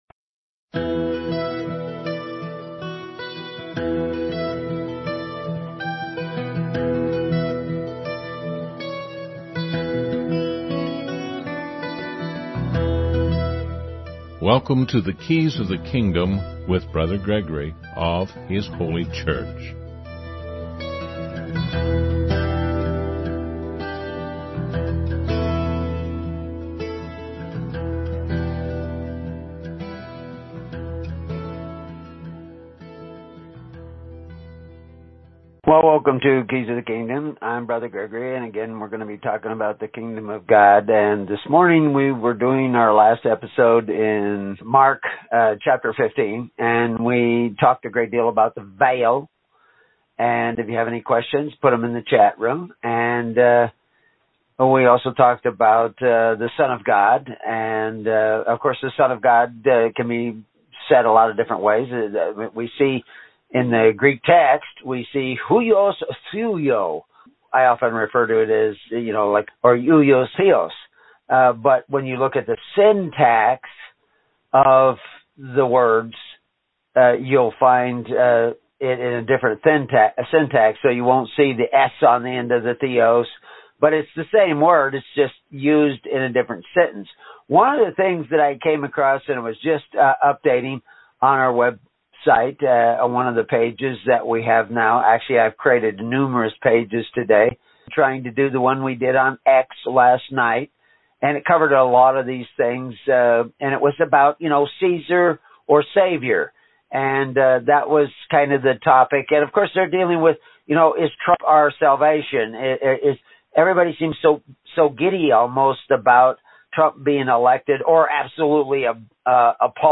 "Keys of the Kingdom" is broadcast weekly (Saturday mornings - 10AM Central Time) on First Amendment Radio.